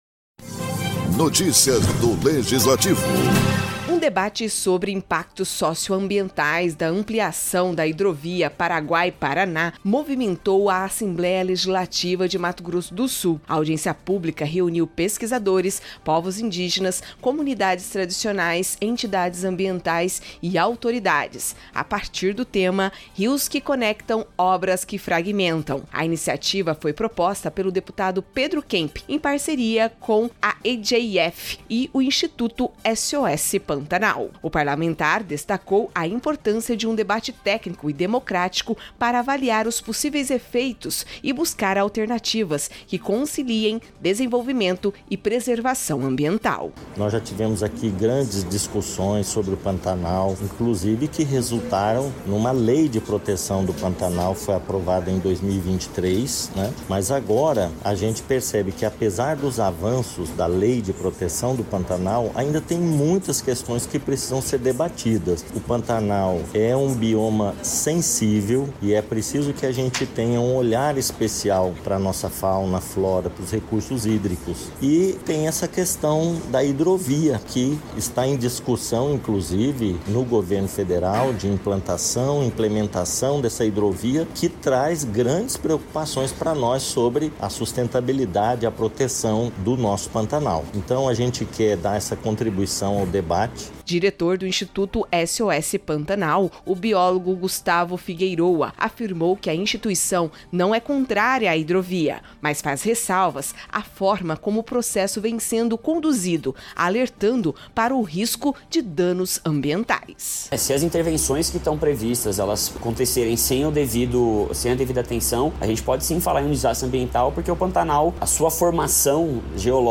A Assembleia Legislativa de Mato Grosso do Sul (ALEMS) realizouuma audiência pública para discutir os efeitos socioambientais da hidrovia Paraguai–Paraná (HPP). O encontro, proposto pelo deputado Pedro Kemp, reuniu especialistas, ribeirinhos, representantes do governo e da sociedade civil, no plenário Júlio Maia.